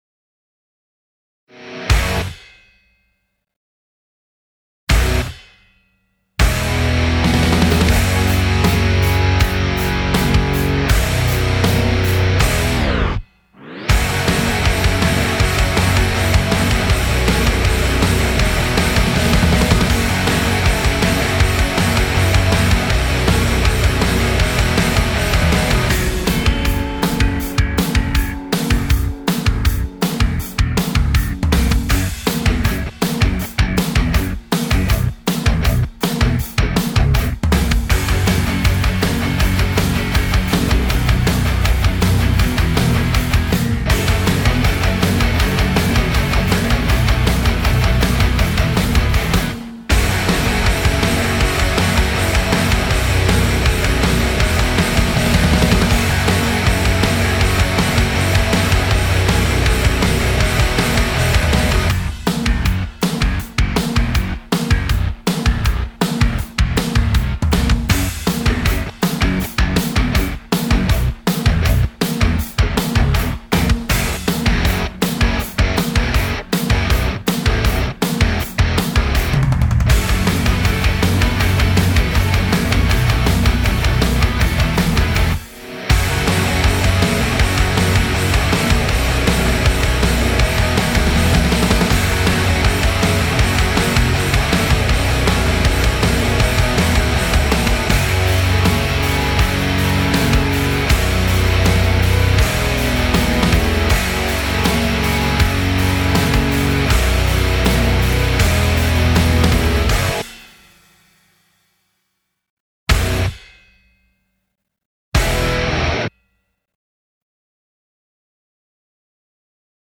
rock remixes